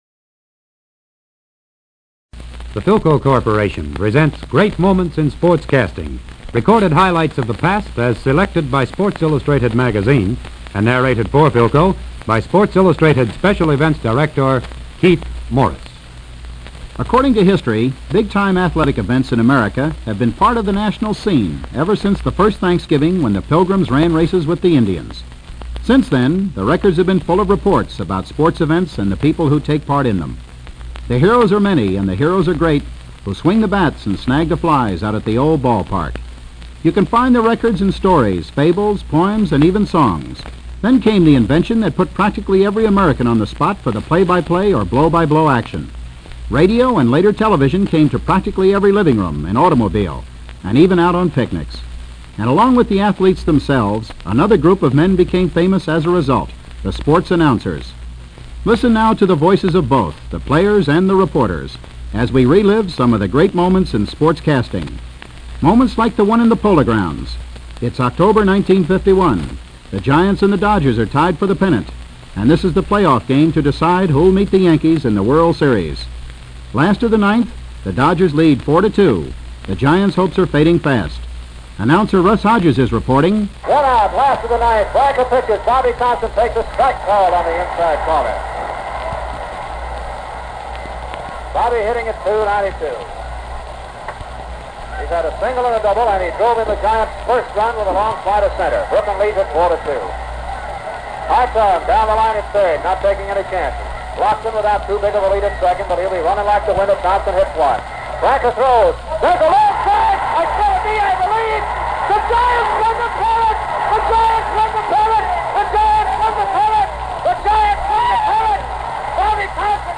The following ’45’ sized 33 rpm record came as a bonus.
October 1951; Dodger vs Giants Pennant Race Post Game Interviews December 7, 1941 Brooklyn vs Giants Football Team Radio Announcement
Knute Rockne in the Notre Dame locker room